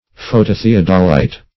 Search Result for " phototheodolite" : The Collaborative International Dictionary of English v.0.48: Phototheodolite \Pho`to*the*od"o*lite\, n. An arrangement of two photographic cameras, the plates of which may be brought into exactly the same plane, used in surveying and map making.
phototheodolite.mp3